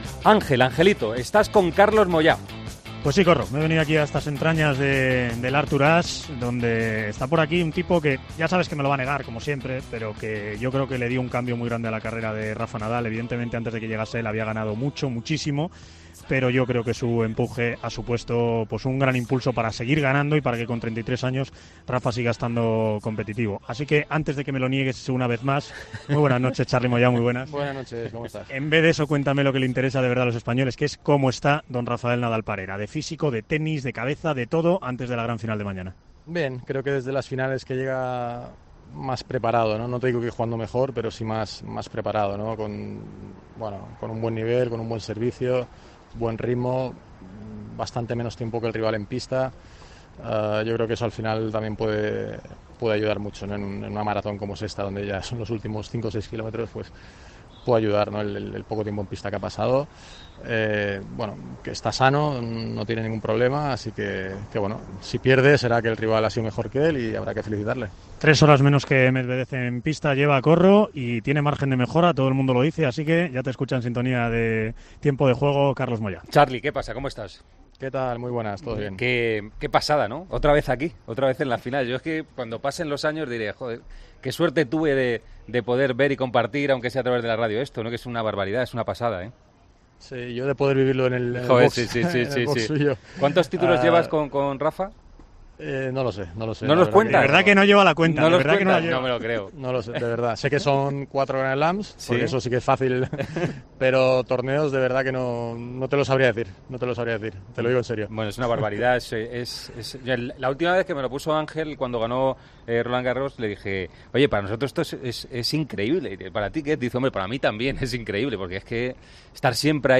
Hablamos con el entrenador de Rafa Nadal antes de la final del US Open que jugará este domingo.